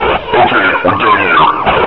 /hl2/sound/npc/combine_soldier/test/near/
player_dead2.ogg